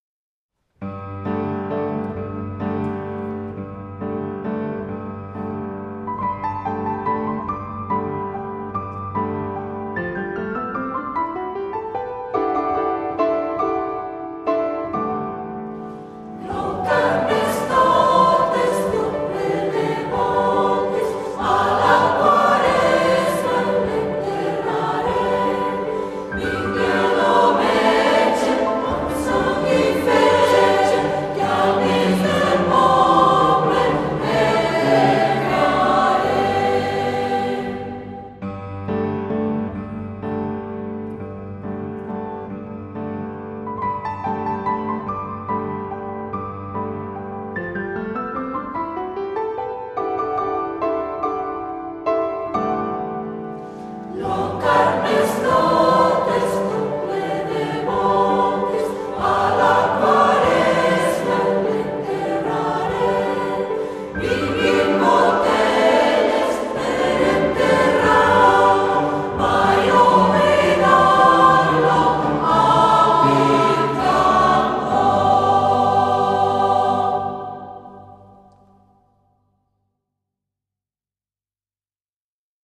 Sol M
Fa# 3 - Re 4